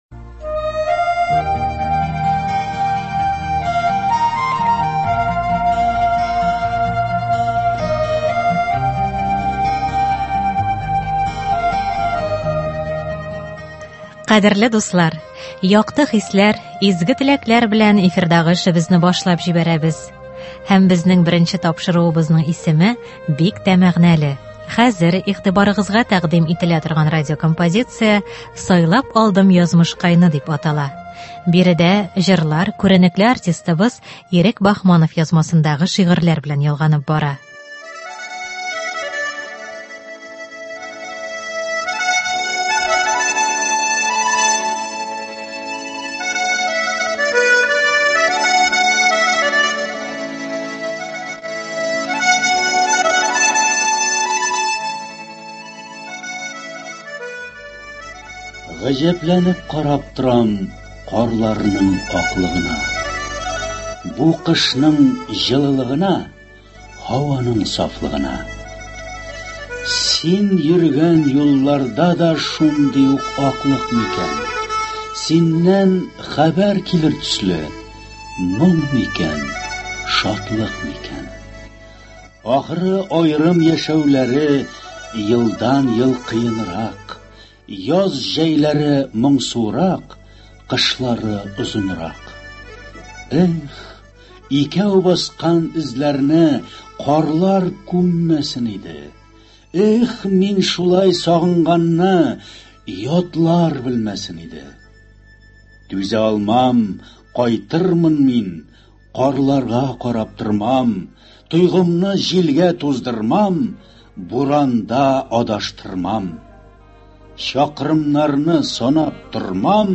Әдәби-музыкаль композиция (06.01.21)